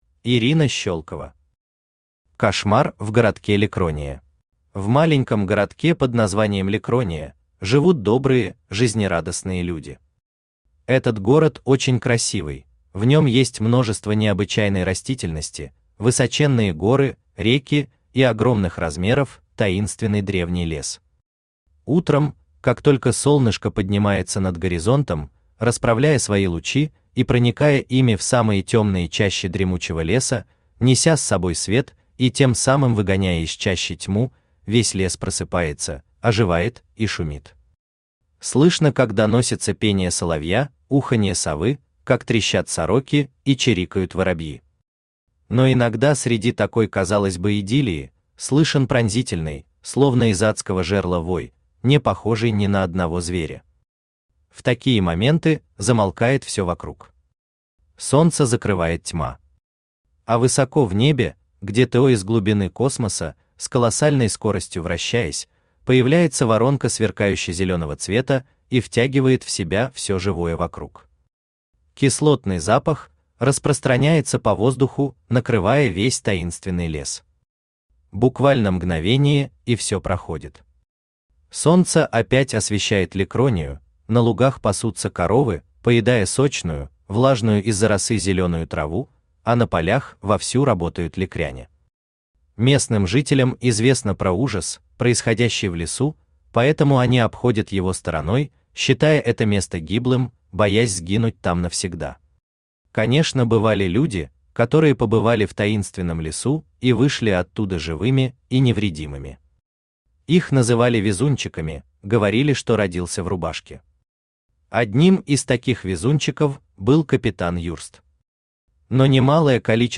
Аудиокнига Кошмар в городке Ликрония | Библиотека аудиокниг
Aудиокнига Кошмар в городке Ликрония Автор Ирина Ивановна Щелкова Читает аудиокнигу Авточтец ЛитРес.